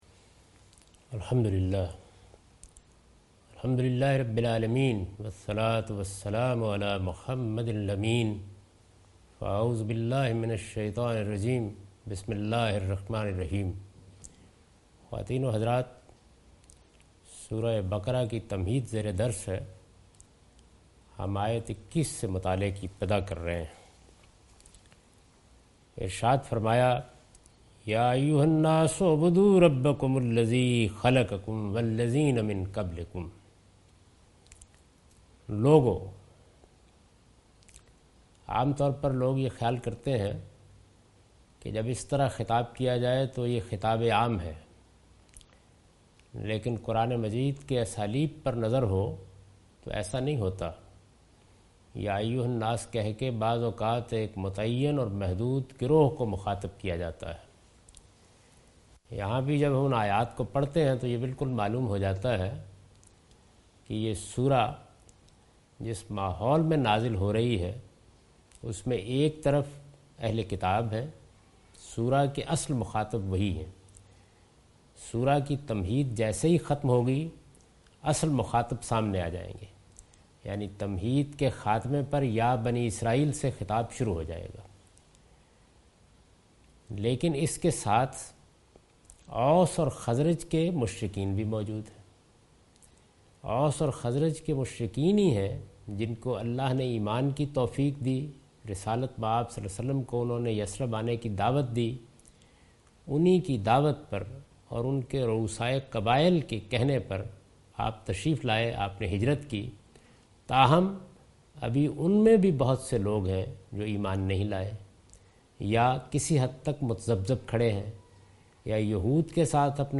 Surah Al-Baqarah - A lecture of Tafseer-ul-Quran – Al-Bayan by Javed Ahmad Ghamidi. Commentary and explanation of verse 20,21,22,23,24 and 25 (Lecture recorded on 11th April 2013).